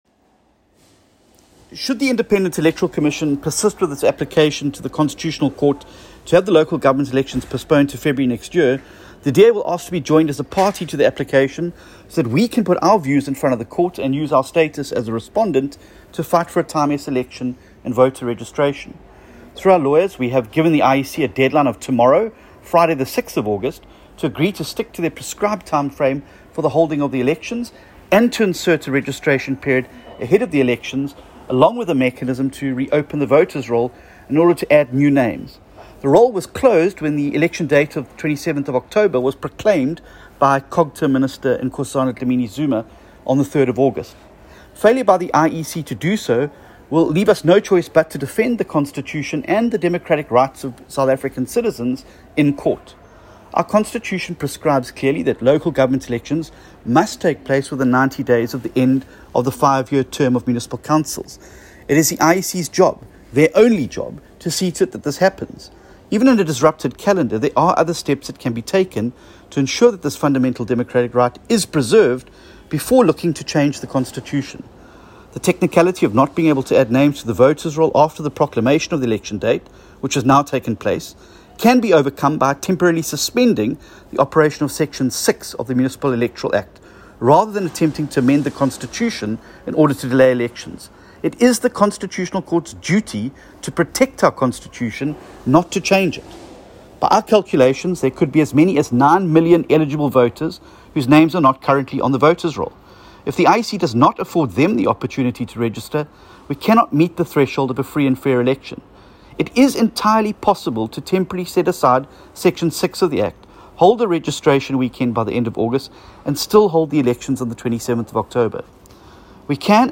soundbite by John Steenhuisen MP.